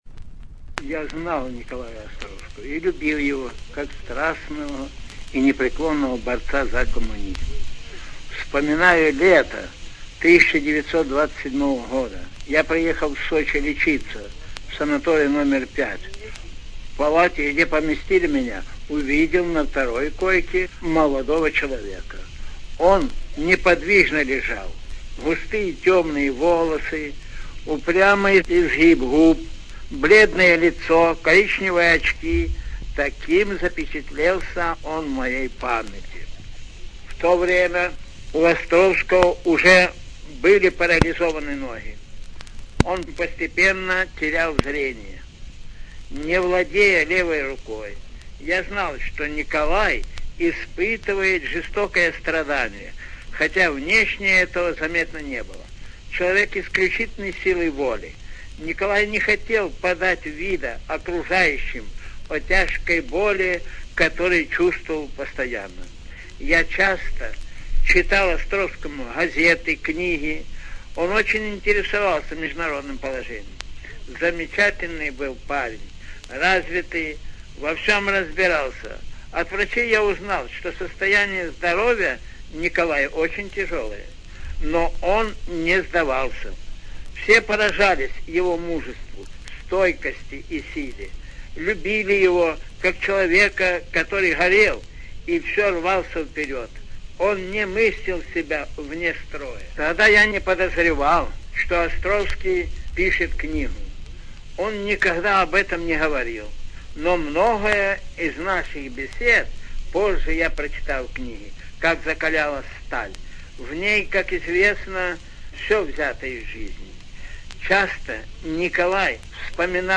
ЖанрБиографии и мемуары, Документальные фонограммы